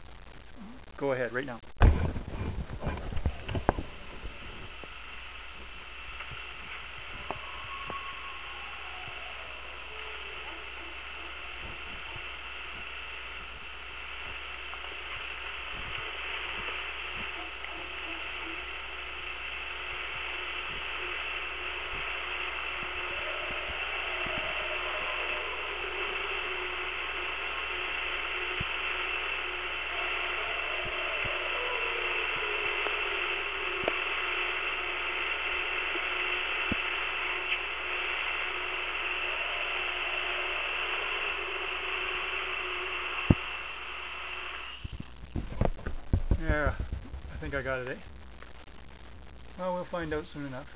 updated with recorded howls....